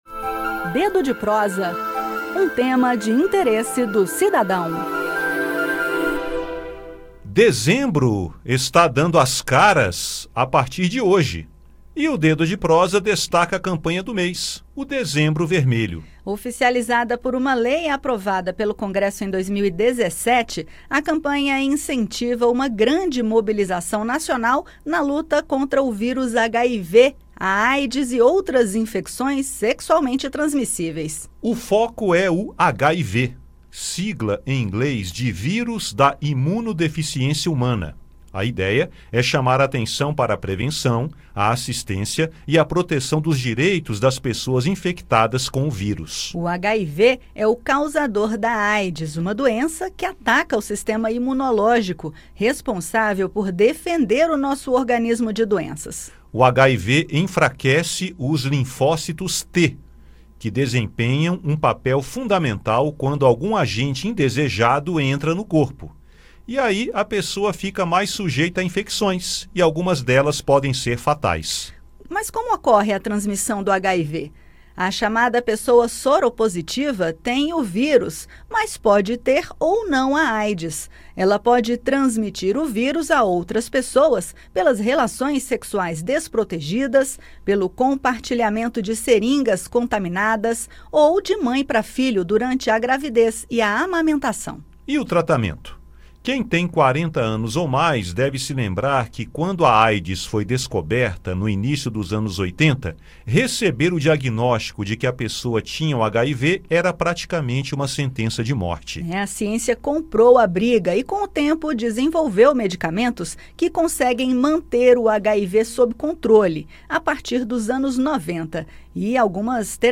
O destaque do bate-papo desta sexta-feira (1) é o Dezembro Vermelho, campanha para chamar a atenção às ações de apoio aos portadores do vírus HIV e conscientizar a população sobre as demais infecções sexualmente transmissíveis (ISTs). Durante todo o mês devem ocorrer atividades e mobilizações relacionadas ao tema.